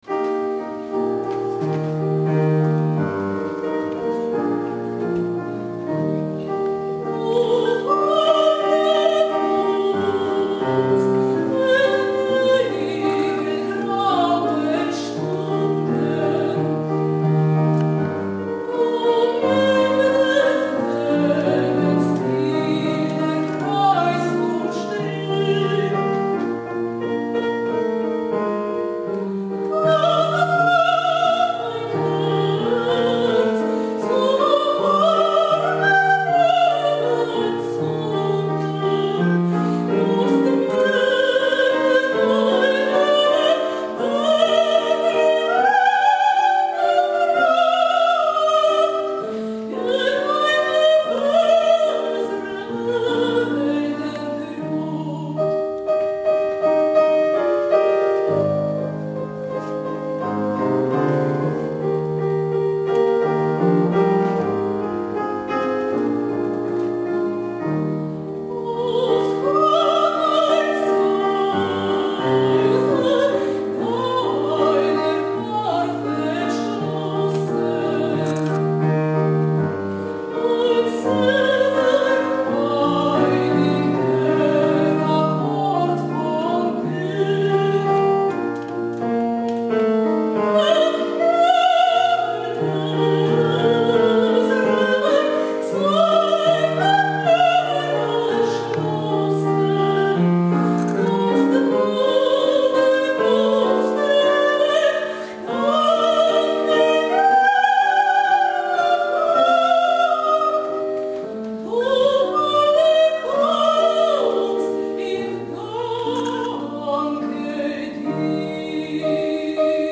драматическое сопрано